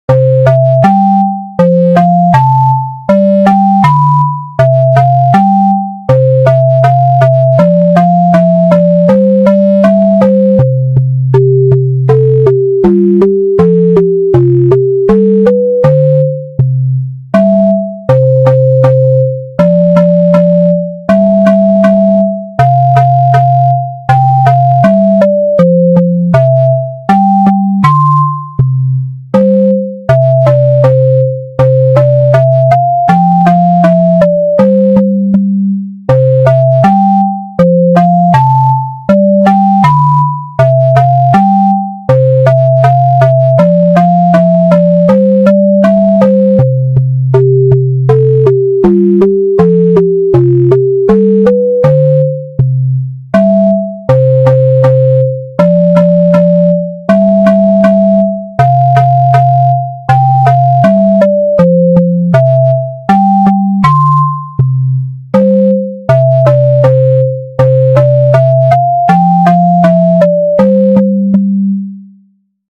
BPM80
ゆっくり 長調